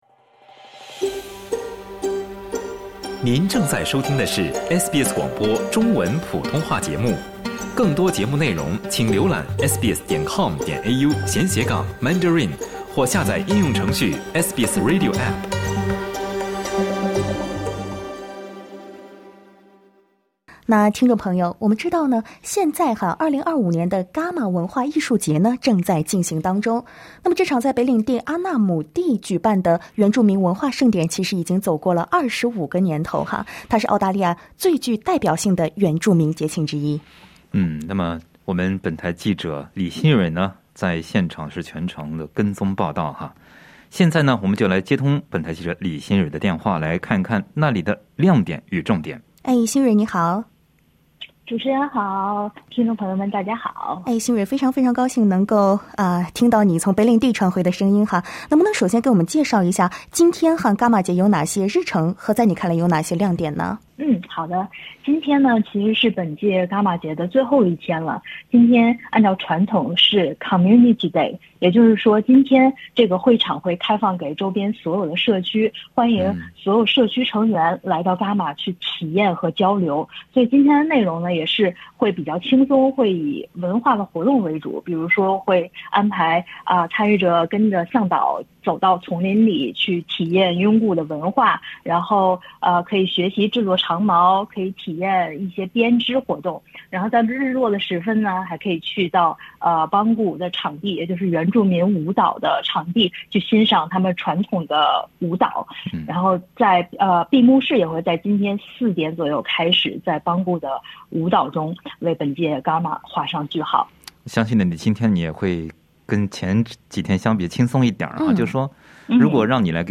第25届伽马节（Garma Festival）将于今天闭幕。SBS中文记者现场直击，闭幕日有哪些亮点？